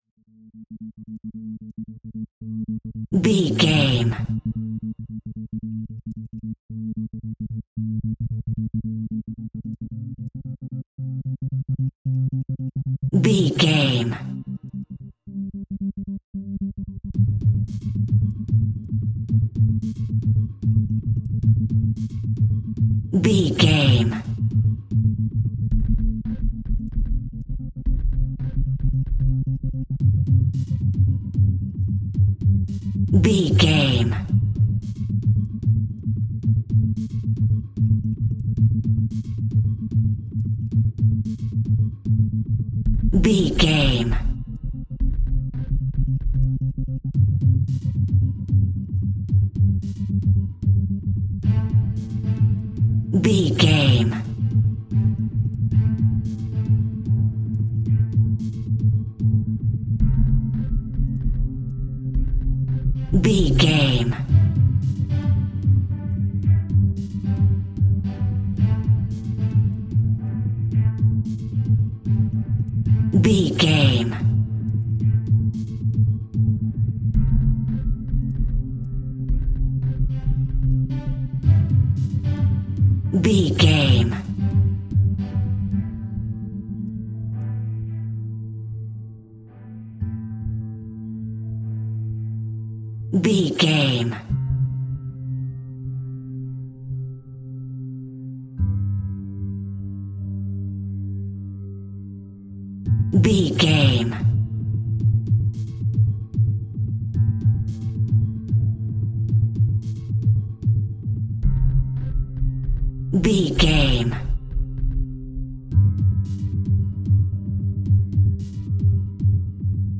In-crescendo
Thriller
Aeolian/Minor
scary
ominous
dark
dramatic
haunting
eerie
strings
percussion
suspenseful
mysterious